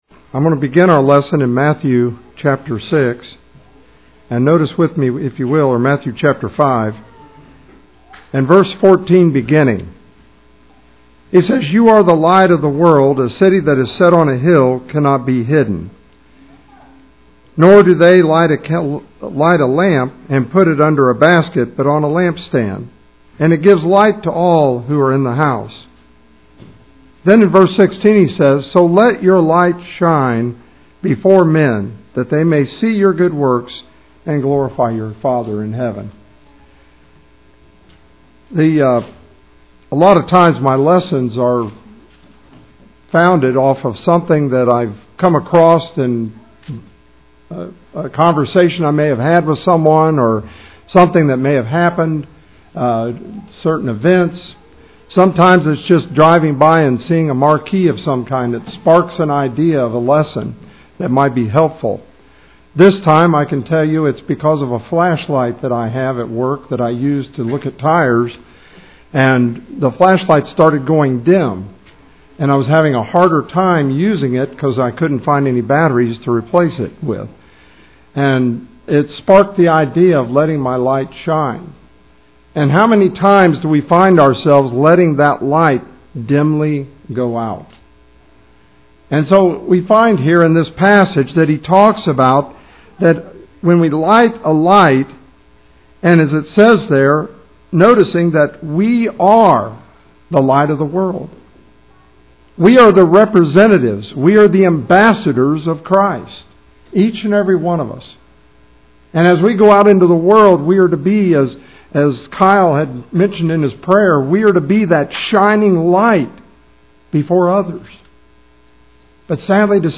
Visiting preacher